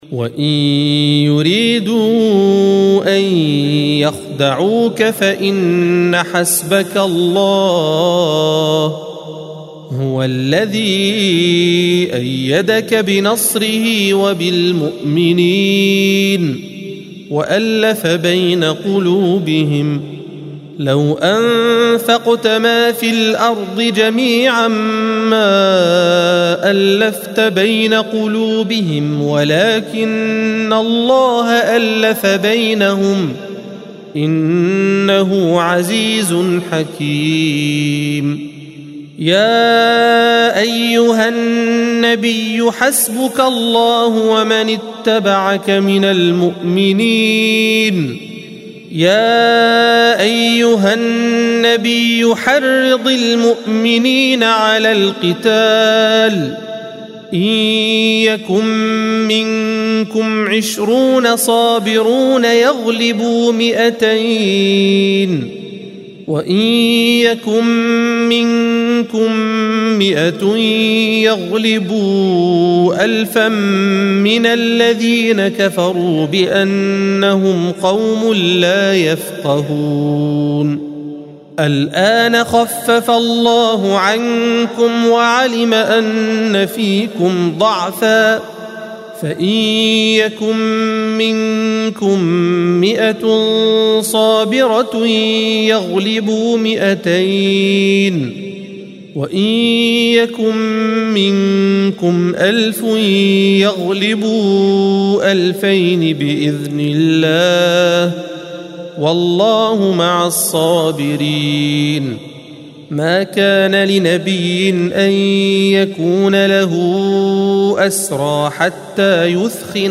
الصفحة 185 - القارئ